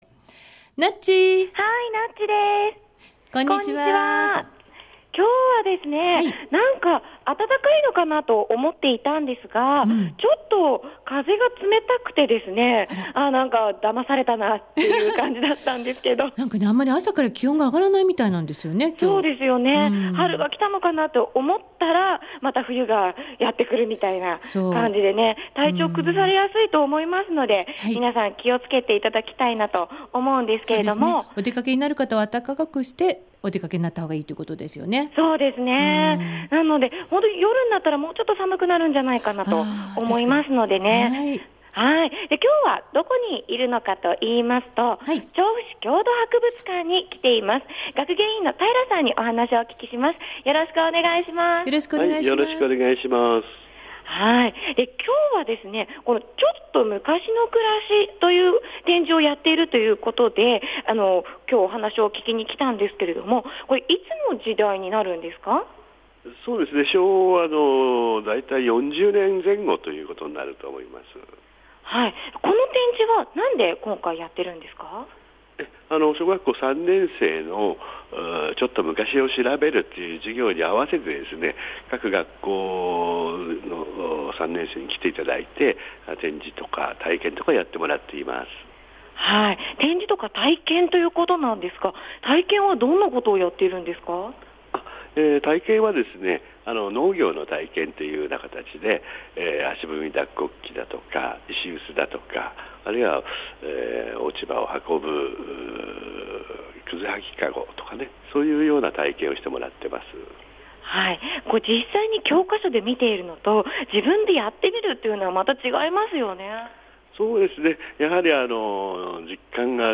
午後のカフェテラス　街角レポート
調布市郷土博物館に行ってきましたー☆